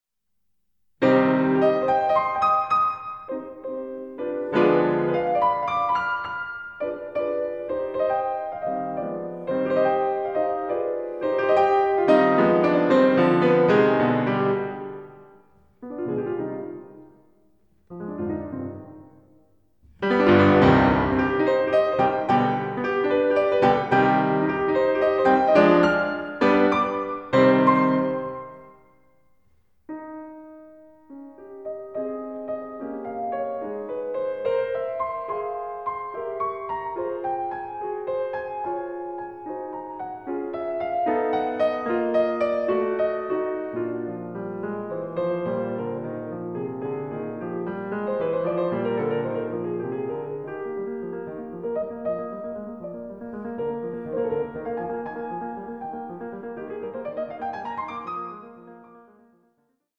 Piano Sonata No.5 in C minor, Op. 10, No. 1